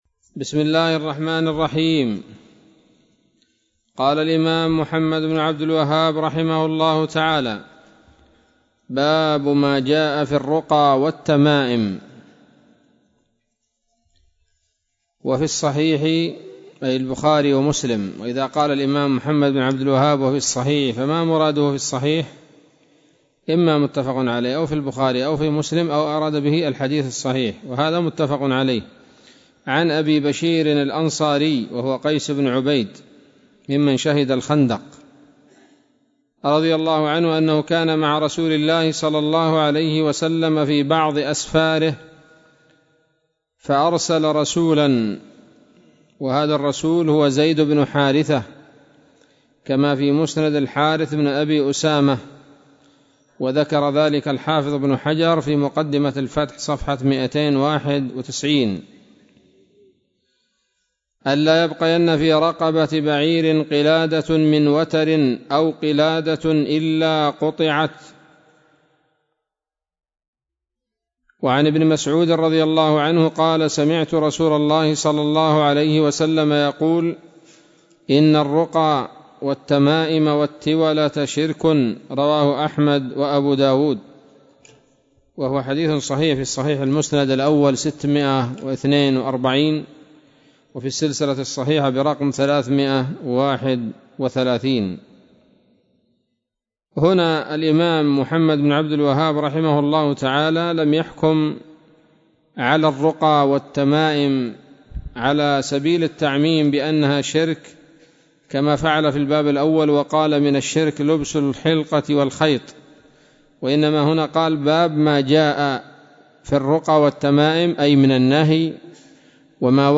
الدرس السابع عشر من كتاب التوحيد للعام 1441هـ